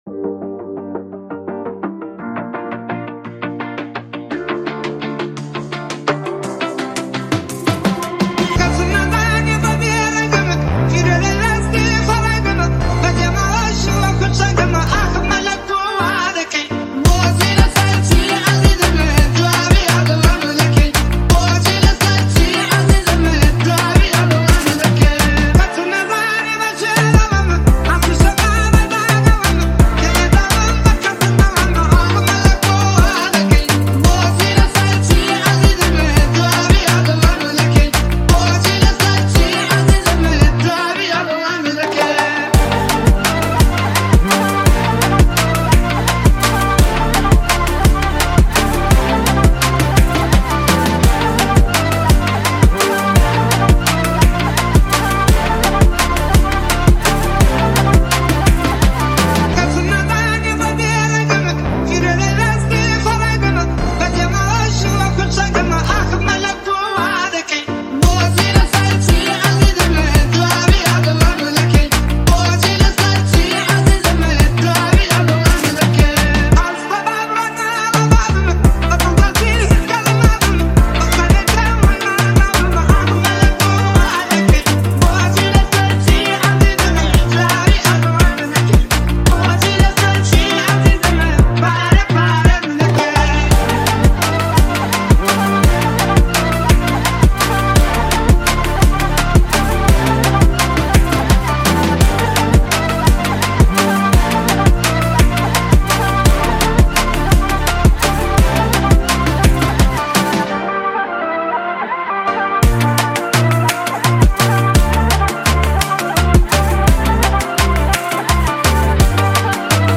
999 بازدید ۱ آبان ۱۴۰۲ ریمیکس , ریمیکس کردی